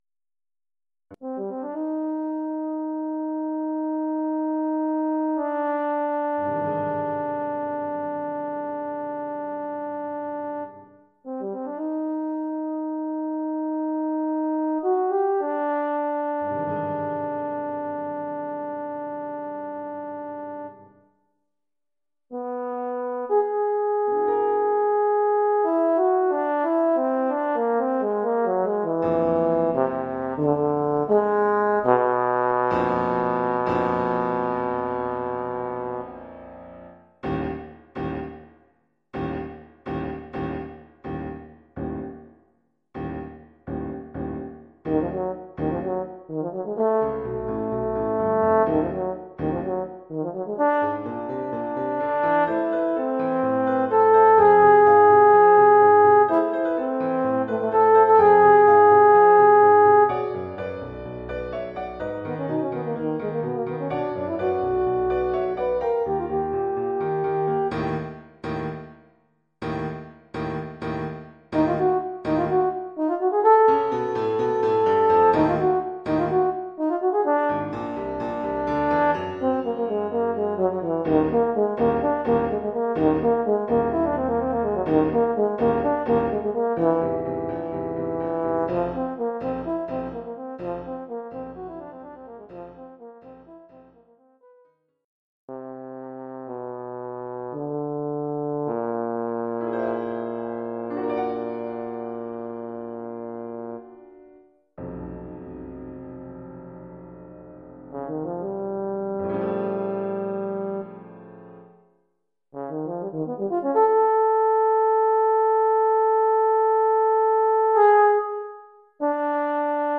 Oeuvre pour saxhorn alto et piano.